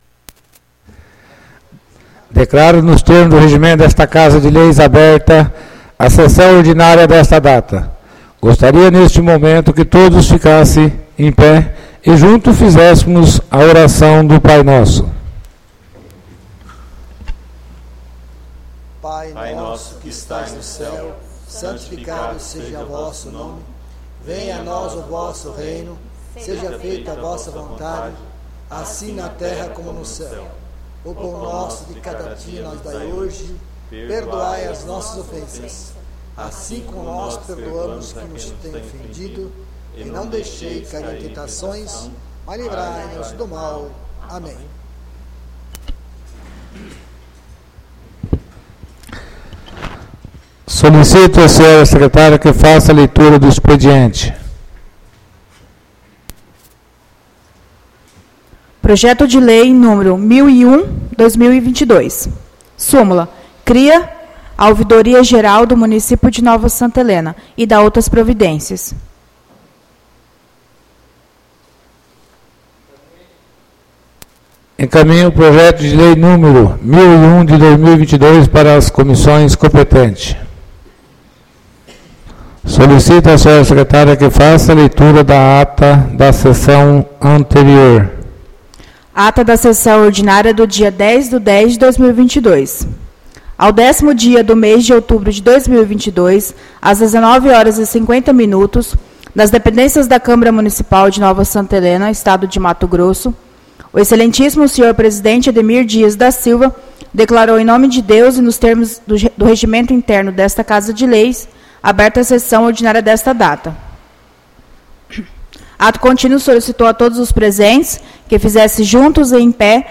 ÁUDIO SESSÃO 17-10-22 — CÂMARA MUNICIPAL DE NOVA SANTA HELENA - MT